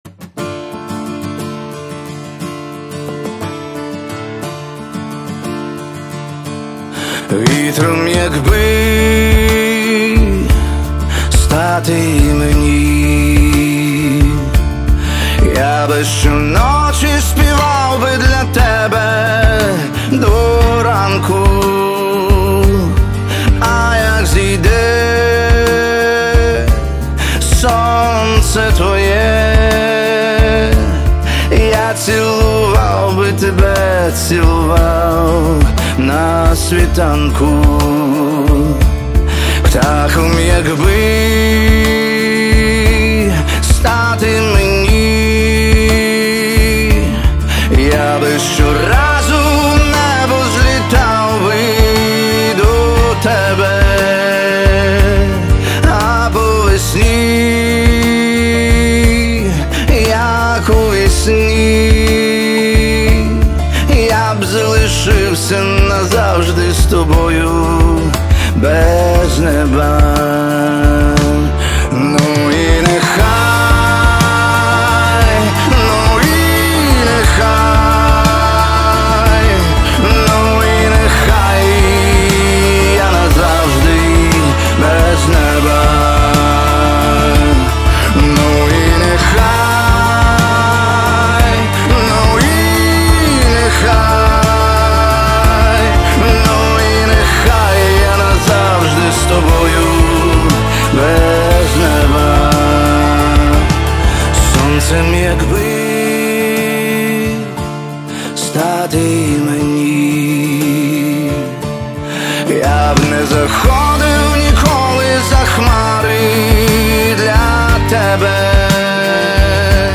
• Жанр:Рок